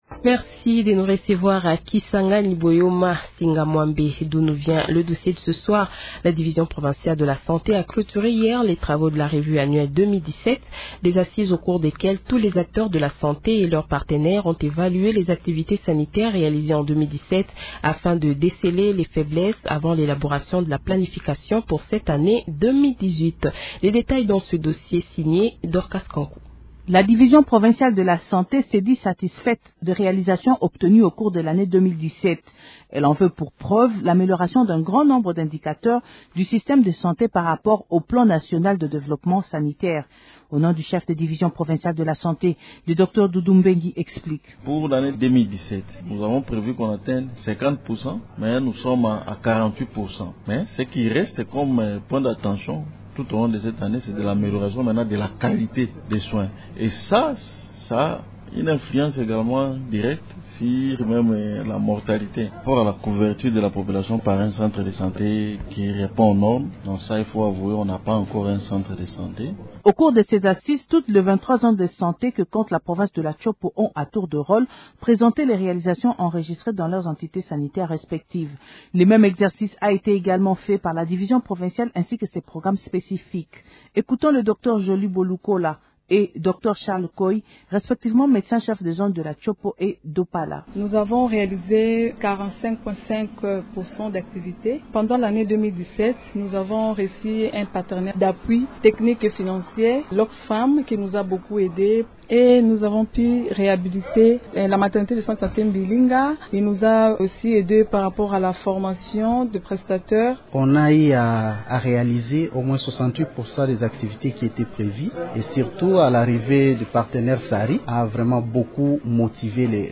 Journal Français Soir